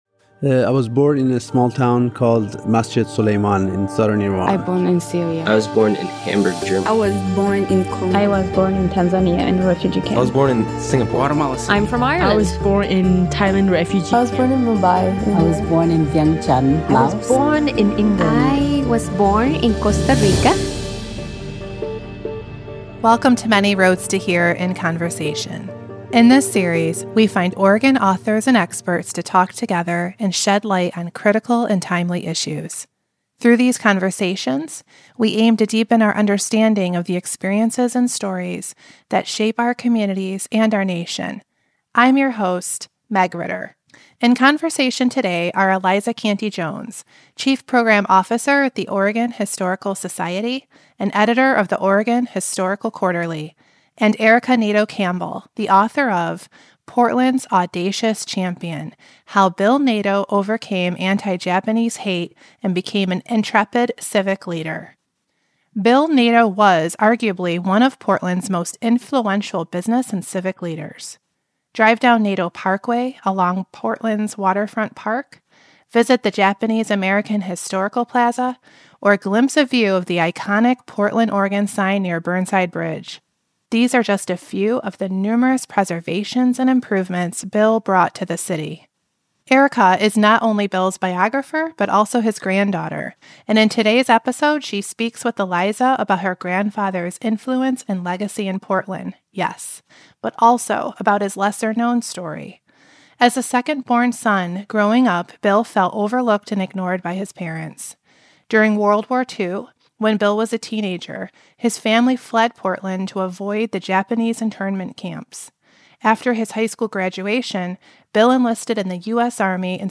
Thank you to St. Andrew Lutheran Church in Beaverton, Oregon for the use of their space to record the interview.